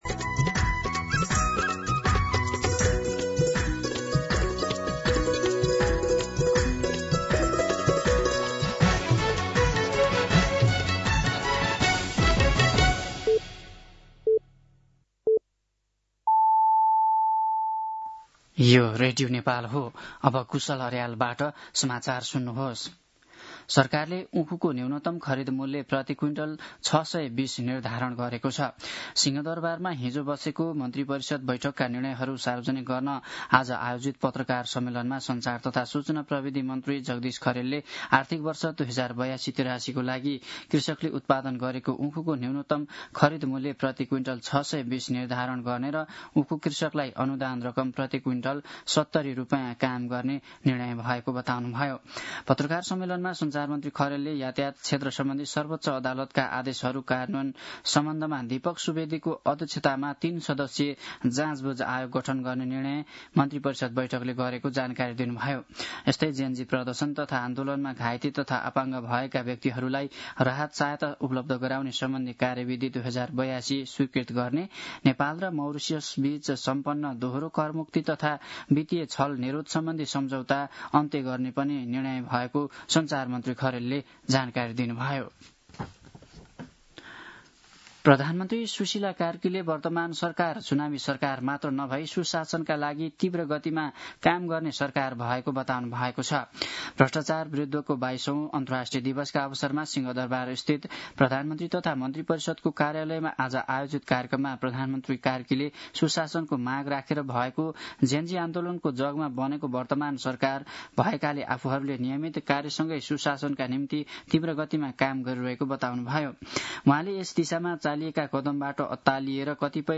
दिउँसो ४ बजेको नेपाली समाचार : २३ मंसिर , २०८२
4-pm-News-08-23.mp3